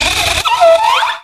infinitefusion-e18/Audio/SE/Cries/WORMADAM.ogg at a50151c4af7b086115dea36392b4bdbb65a07231